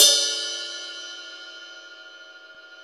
CYM XRIDE 3D.wav